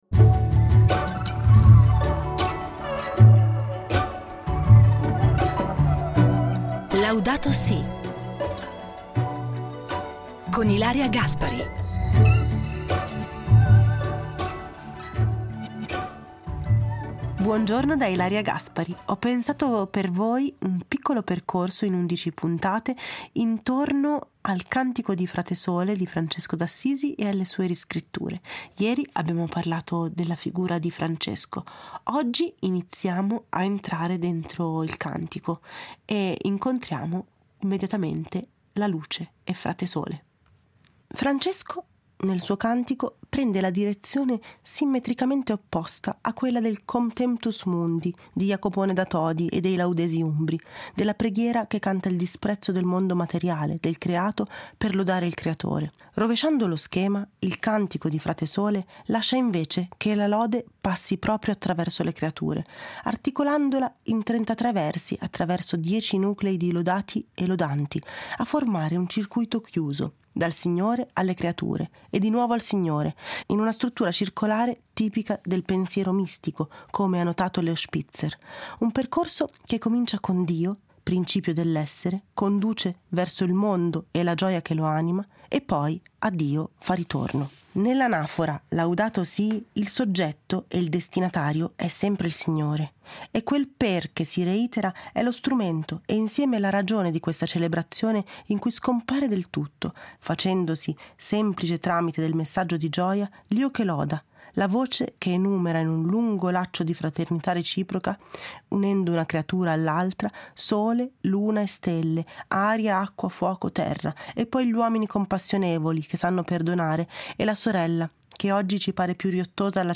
Il Cantico dei cantici letto da Ilaria Gaspari